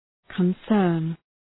Προφορά
{kən’sɜ:rn}